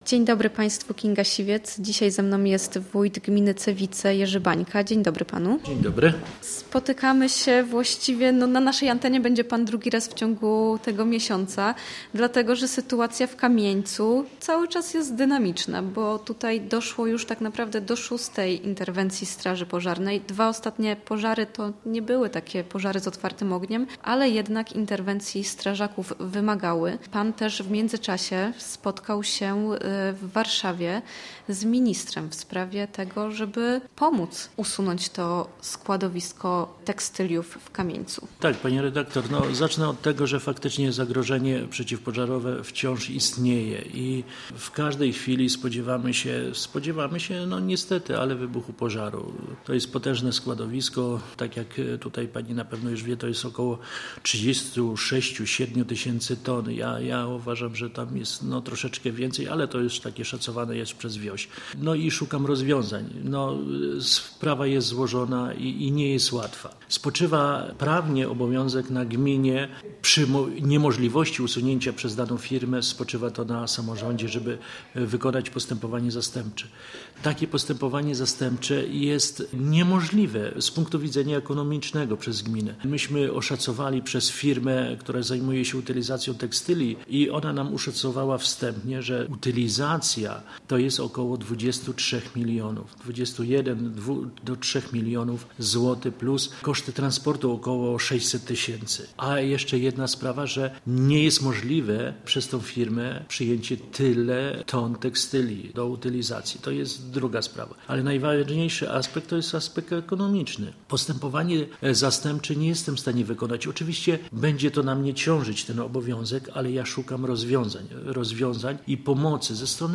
Wójt gminy Cewice Jerzy Bańka, gość Studia Słupsk, przybliżył na antenie szczegóły.